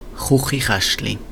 Stimmlose uvulare Frikativ Hochalemannisch: Chuchichäschtli; Chuchichaeschtli.ogg
[ˈʁ̥ʊχːiˌʁ̥æʃtli](info) ‚Chùchichäschtli‘